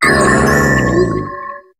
Cri d'Archéodong dans Pokémon HOME.